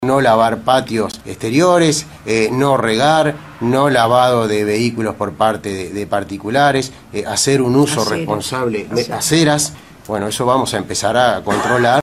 En una conferencia de prensa realizada luego de la reunión de la Comisión de Cuenca del Río Santa Lucía, el presidente de OSE, Milton Machado, dijo que en la medida que no se pronostican lluvias que contemplen la recuperación de acuíferos y restablezcan la situación normal de los caudales, OSE se ve obligado a tomar esta postura.
Palabras de Milton Machado